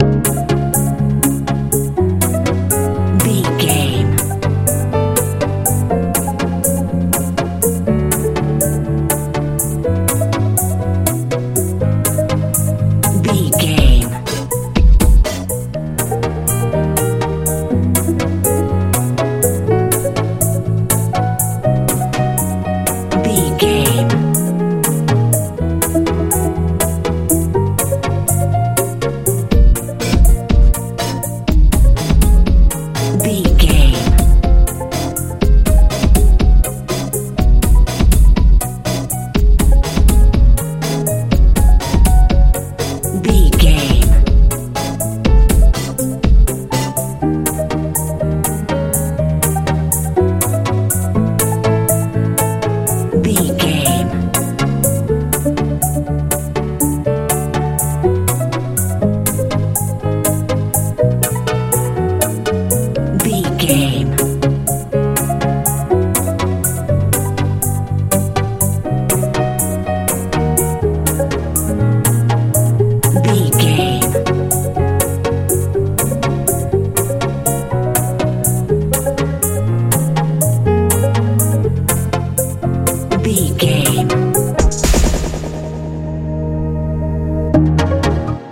modern pop feel
Ionian/Major
C♯
mystical
strange
piano
bass guitar
drums
synthesiser
80s
90s